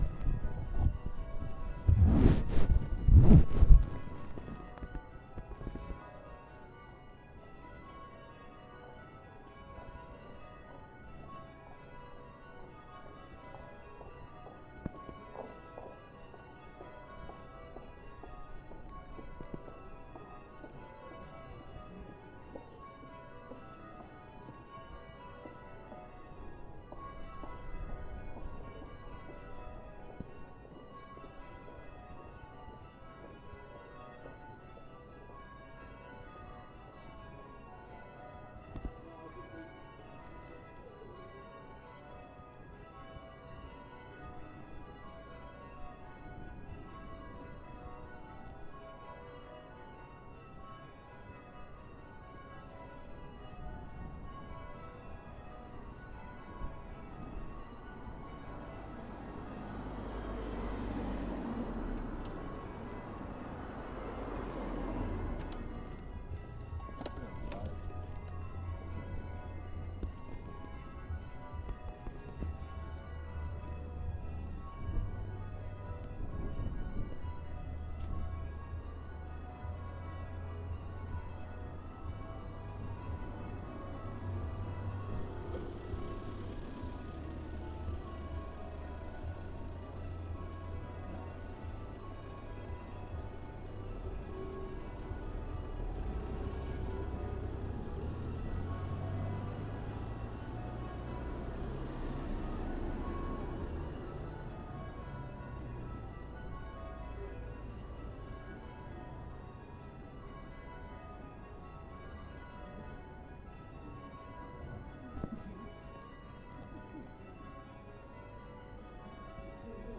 St George's Day Bells 3. With a few cars, but bells sound closer